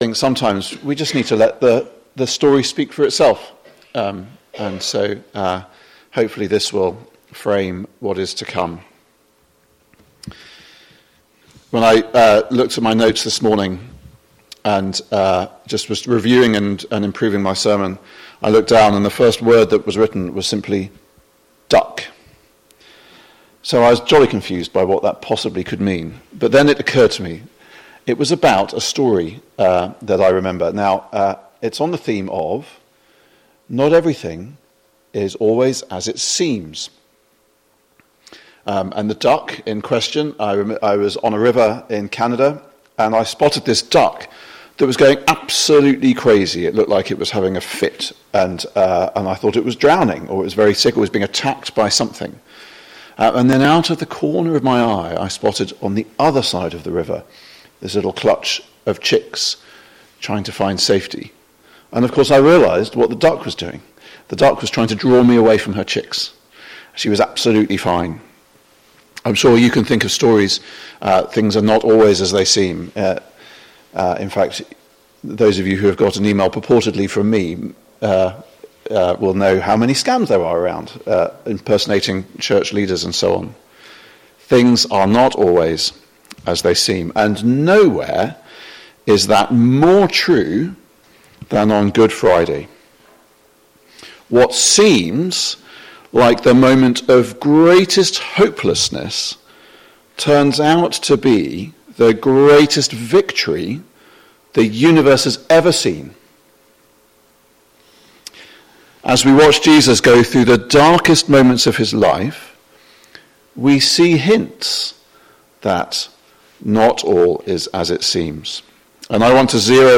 GOOD-FRIDAY-2025-JOINT-SERVICE.mp3